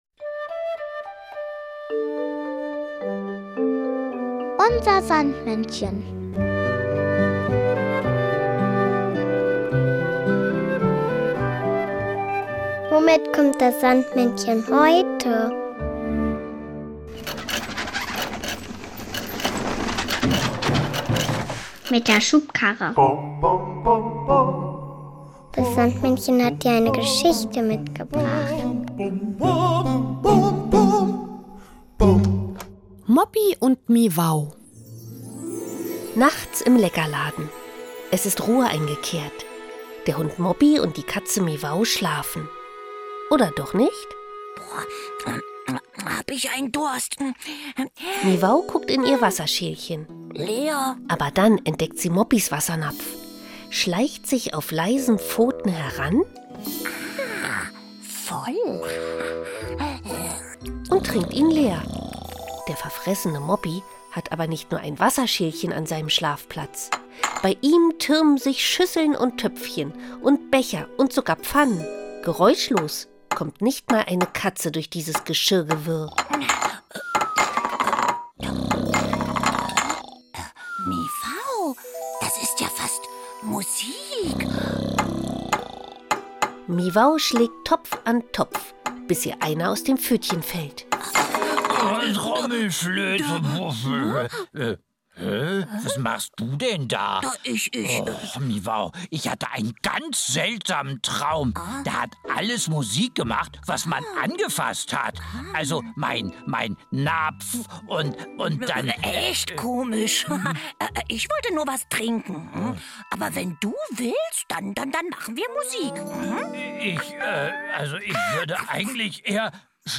Sandmännchen hat dir aber nicht nur diese Geschichte mitgebracht,